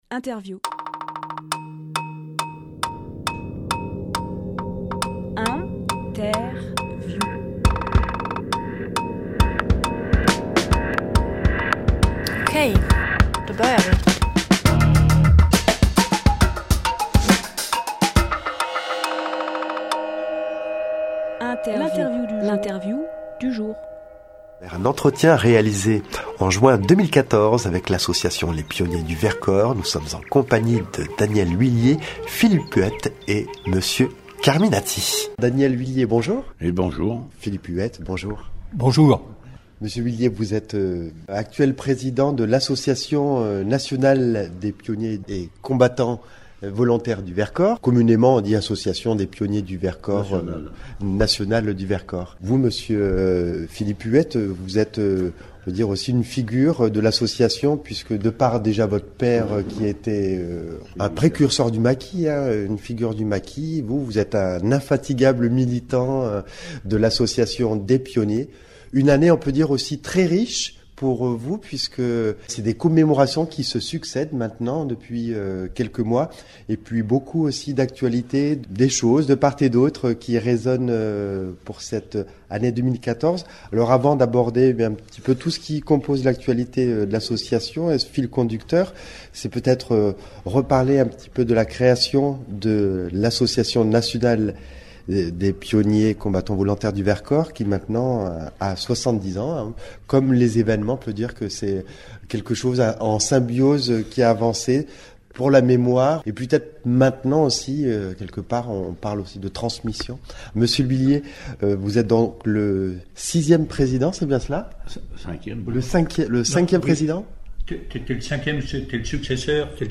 Emission - Interview Pionniers du Vercors Publié le 14 janvier 2026 Partager sur… Télécharger en MP3 Entretien avec l’association des pionniers du Vercors (Association nationale des pionniers et combattants volontaires du maquis du Vercors) à l’occasion des commémorations du 8 mai 1945. Autour de notre micro, au siège de l’association à Grenoble